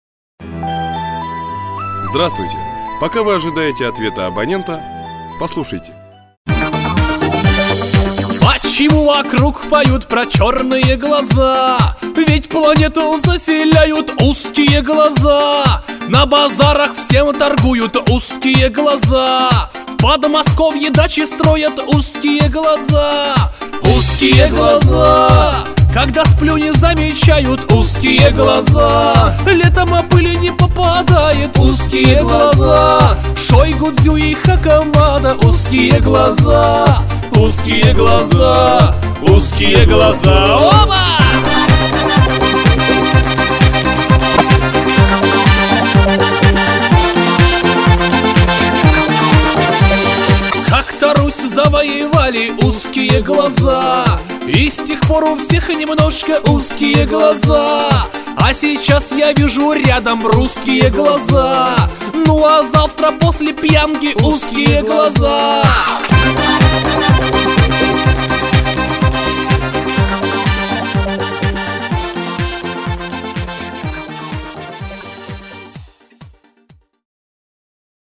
Энергичная  танцевальная  кавказская песня про узкие глаза..